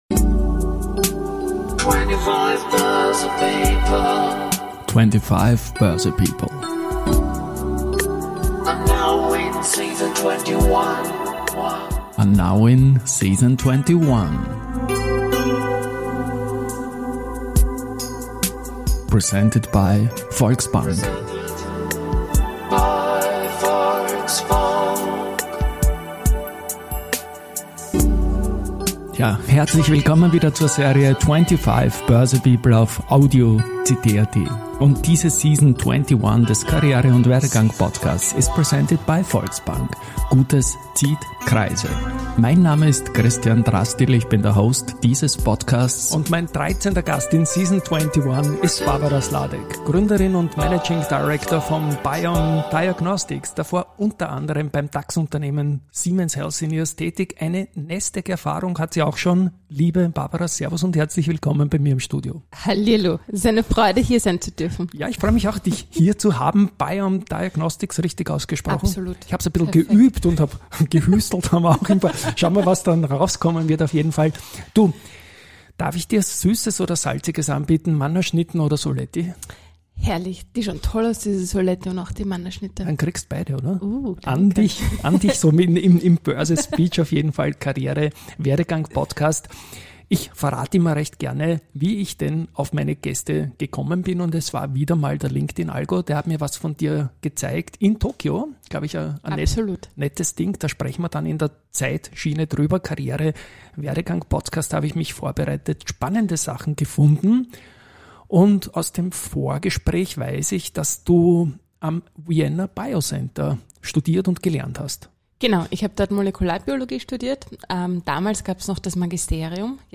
Es handelt sich dabei um typische Personality- und Werdegang-Gespräche.